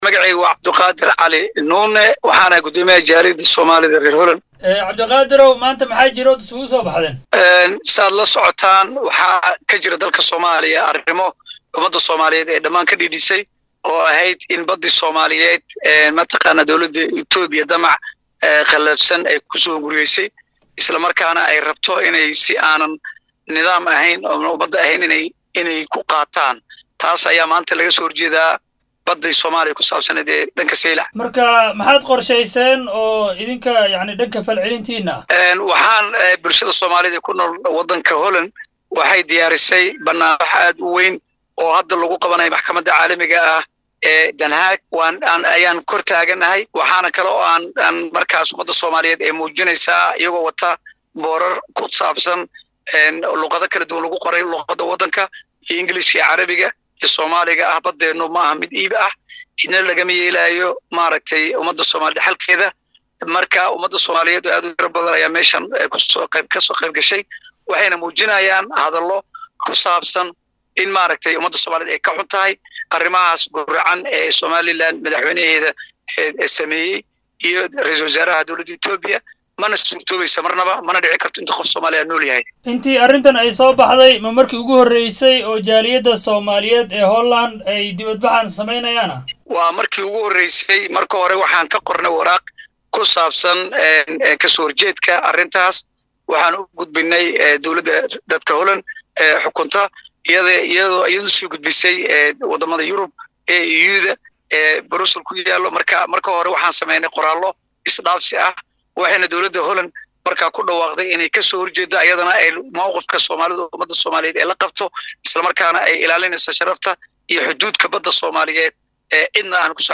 Wareysi-Bannaanbaxa-Soomaalida-ee-ICJ.mp3